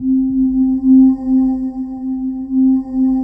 20PAD 01  -R.wav